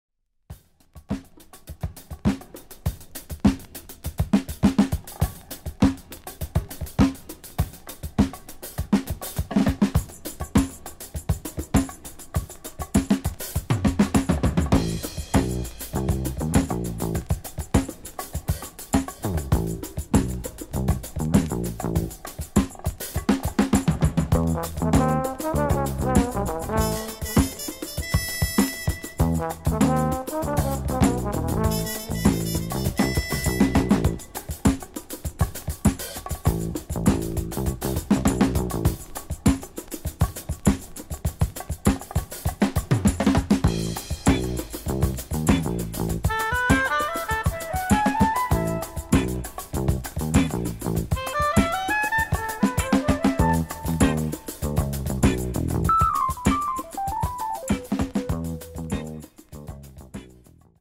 Funk / Soul / Disco